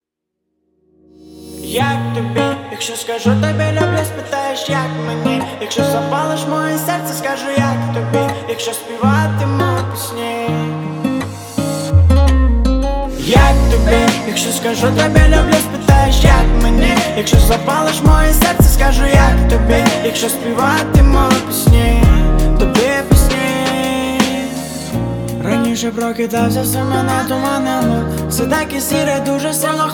Жанр: Поп / Украинский рок / Украинские